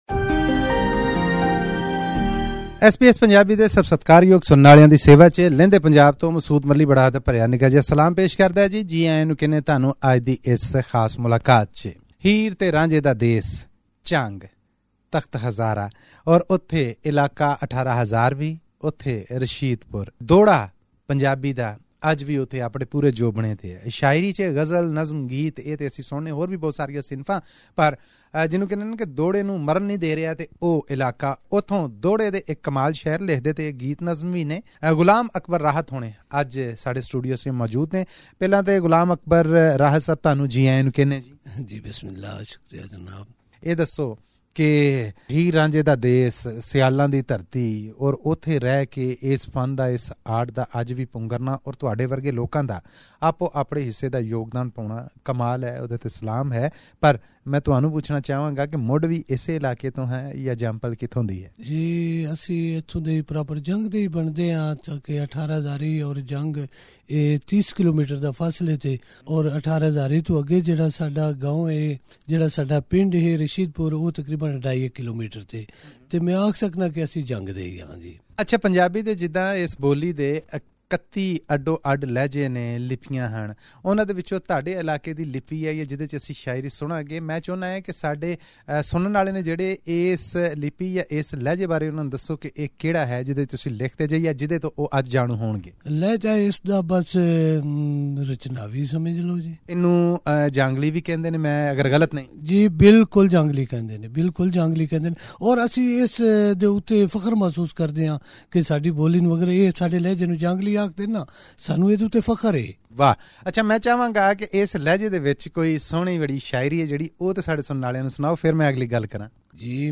Listen to this interview in Punjabi by clicking the player at the top of the page.